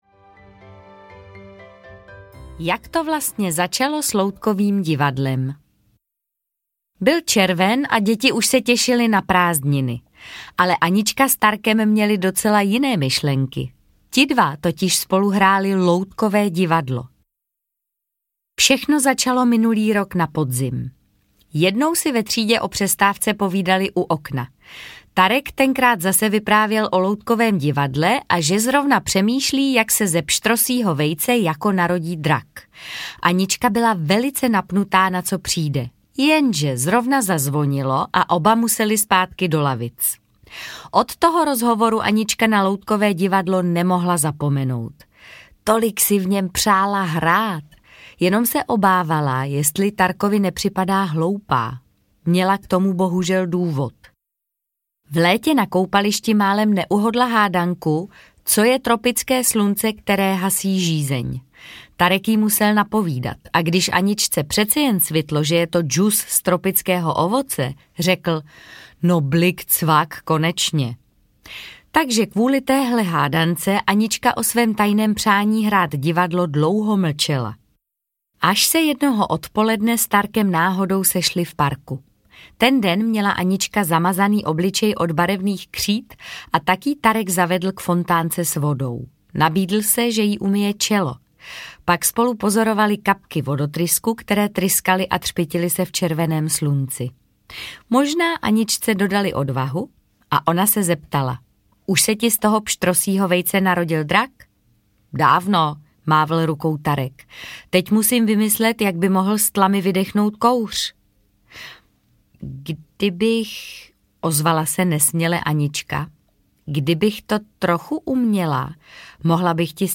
Anička a divadlo audiokniha
Ukázka z knihy
• InterpretMartha Issová